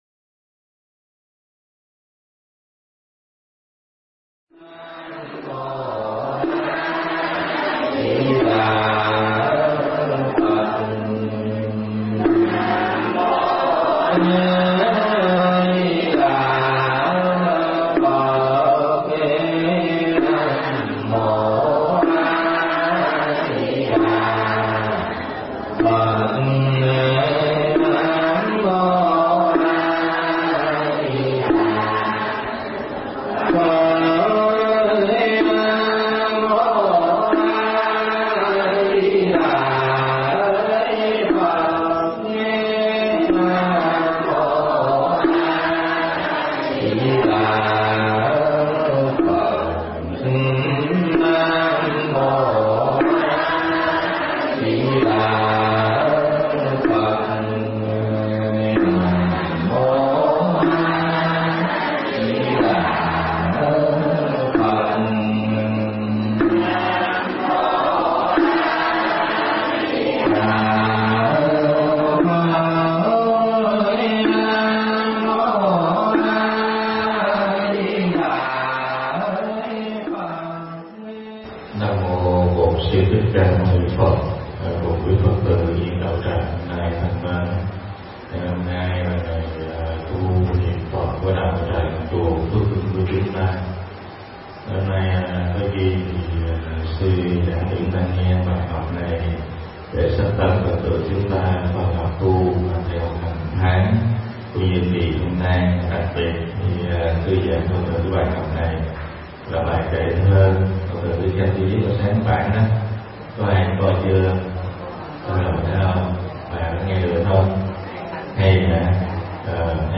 Mp3 Thuyết Pháp Đi Chùa Học Và Tu
Giảng Tại Chùa Phước Linh